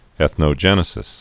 (ĕthnō-jĕnĭ-sĭs)